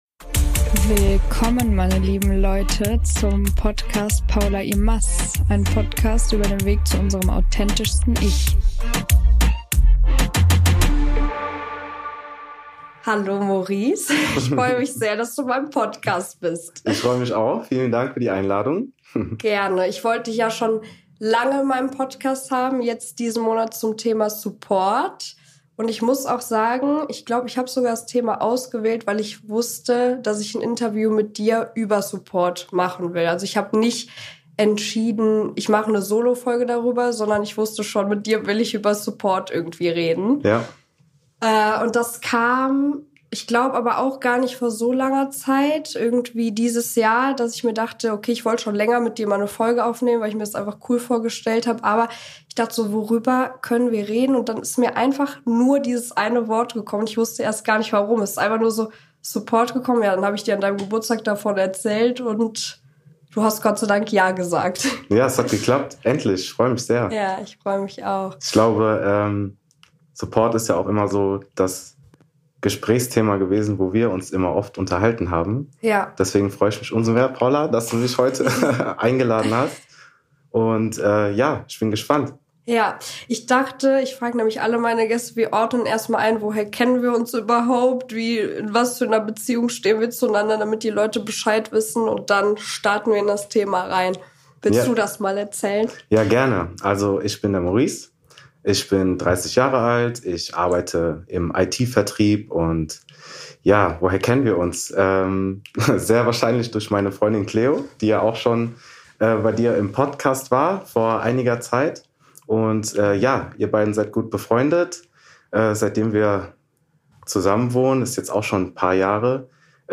Taucht ein in eine spannende, ehrliche und offene Unterhaltung über Support, aber auch noch viel mehr.